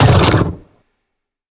biter-roar-big-3.ogg